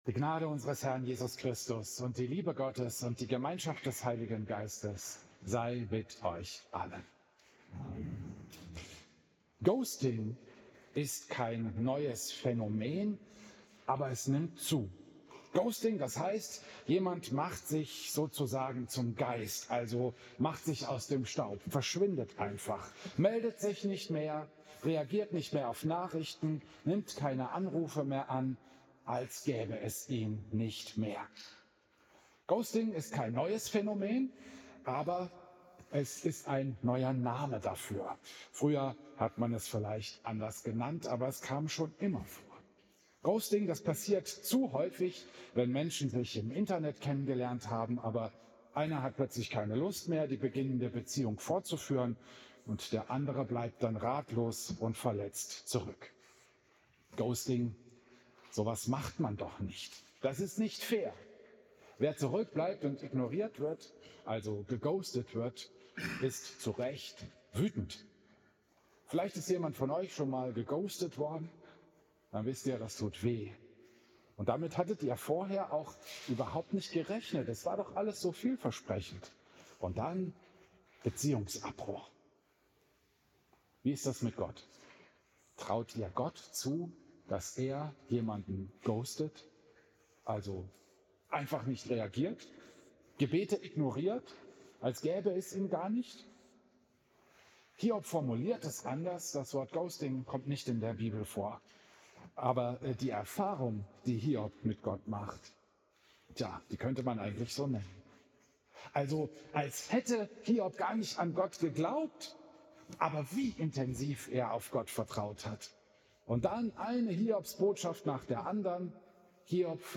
Predigt
Klosterkirche Volkenroda, 31. August 2025